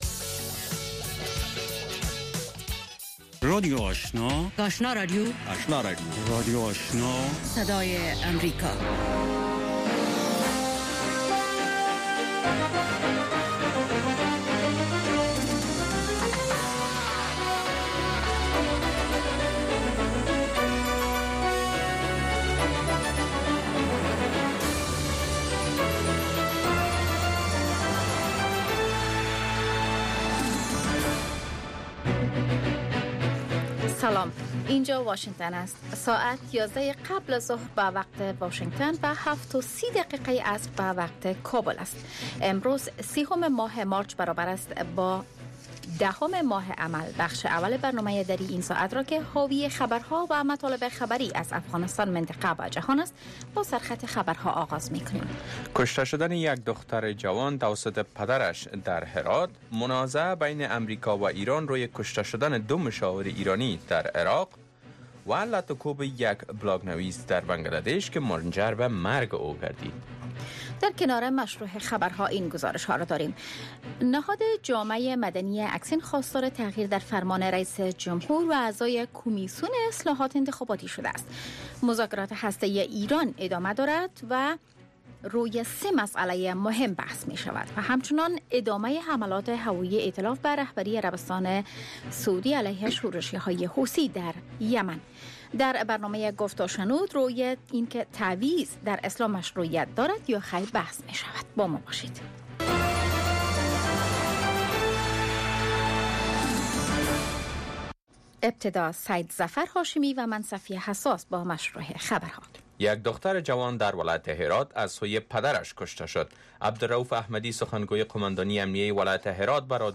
در اولین برنامه خبری شب، خبرهای تازه و گزارش های دقیق از سرتاسر افغانستان، منطقه و جهان فقط در سی دقیقه.